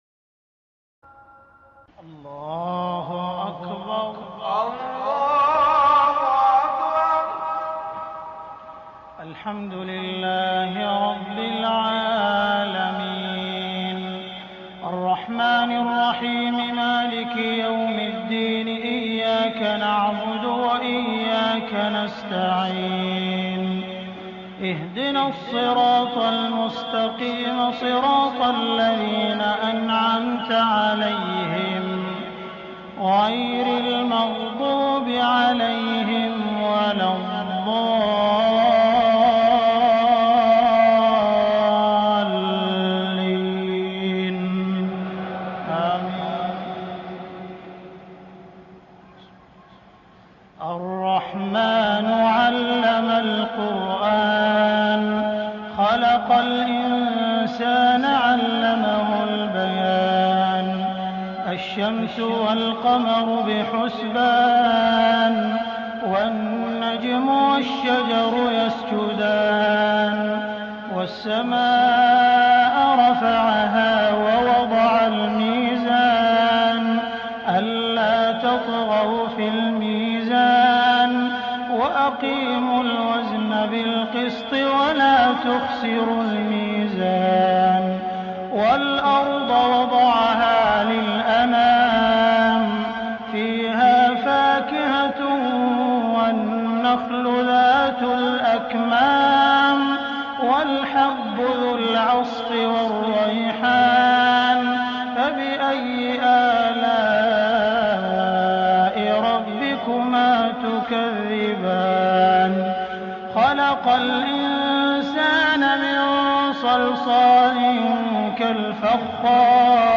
تهجد ليلة 30 رمضان 1418هـ سورتي الرحمن و الملك Tahajjud 30 st night Ramadan 1418H from Surah Ar-Rahmaan and Al-Mulk > تراويح الحرم المكي عام 1418 🕋 > التراويح - تلاوات الحرمين